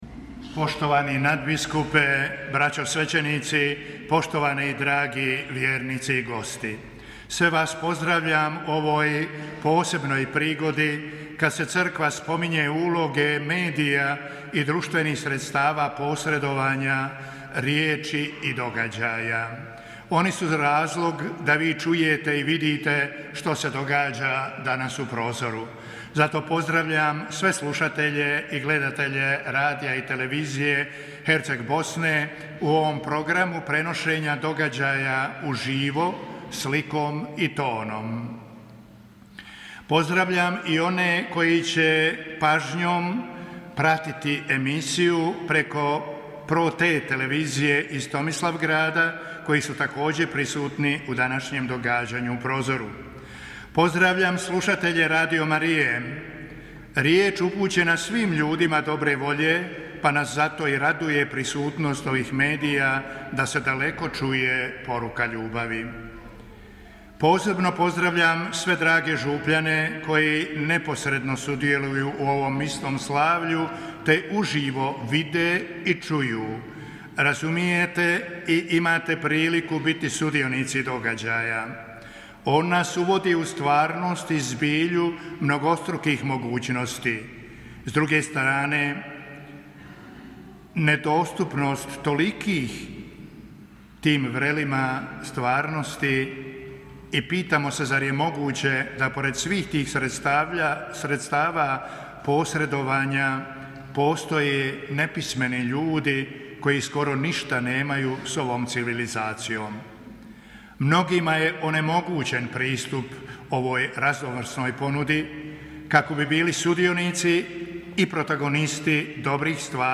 Nadbiskup Vukšić predvodio Euharistijsko slavlje u Prozoru na 58. Svjetski dan sredstava društvenog priopćavanja u BiH
Misno slavlje izravno je prenosila Radiotelevizija Herceg Bosne i Radio Marija BiH.